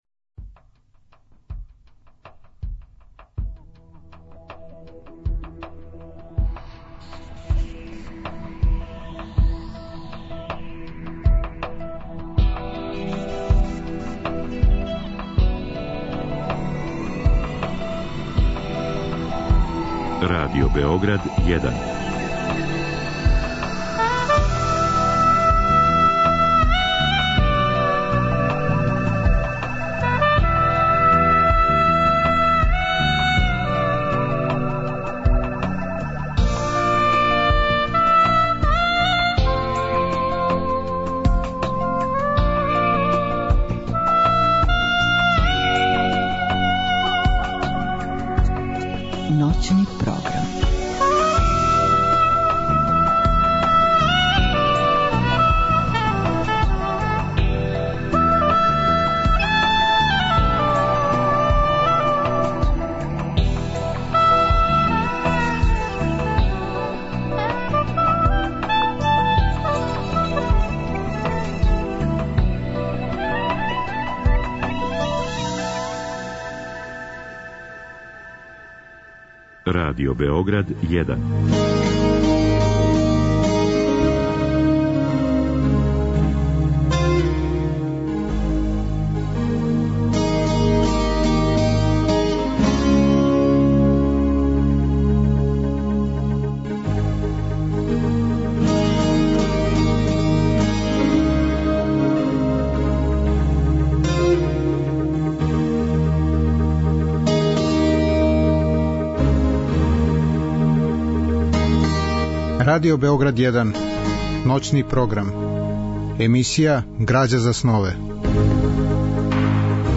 Разговор и добра музика требало би да кроз ову емисију и сами постану грађа за снове.
У другом делу емисије, од два до четири часa ујутро, слушаћемо делове радио-драме Кротка Фјодора Михајловича Достојевског.